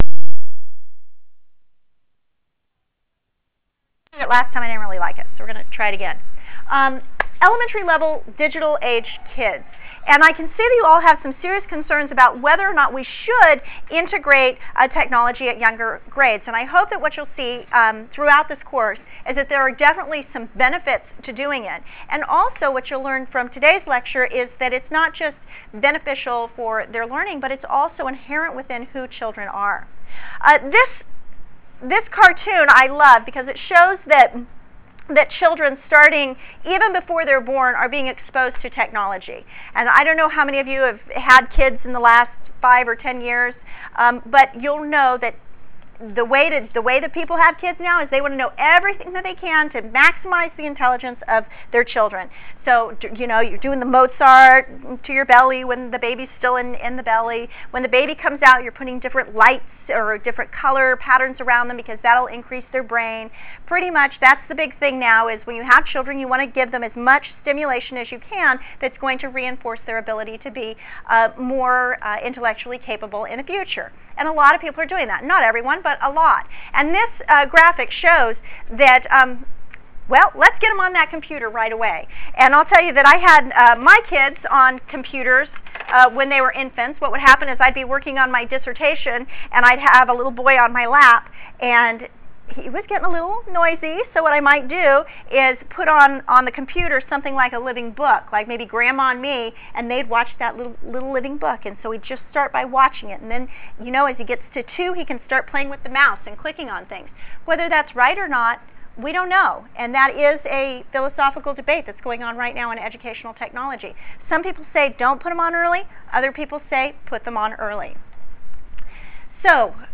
Lecture_Millennials1_22_07.wav